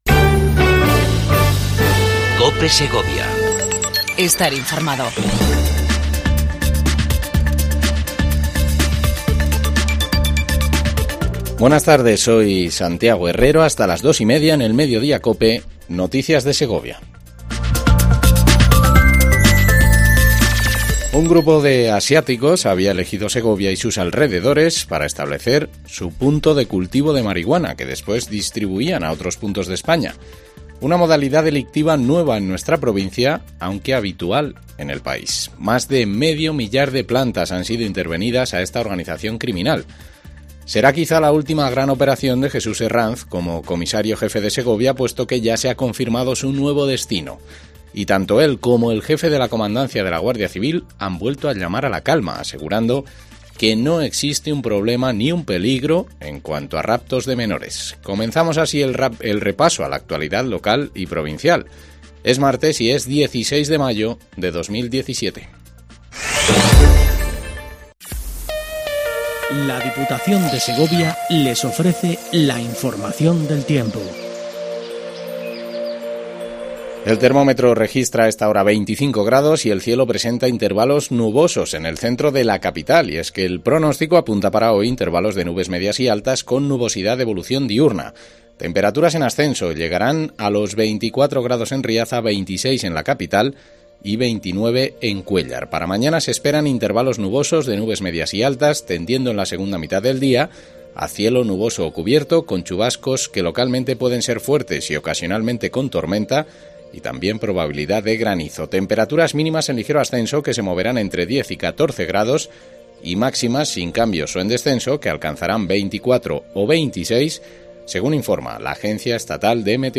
INFORMATIVO MEDIDIA COPE EN SEGOVIA 16 05 17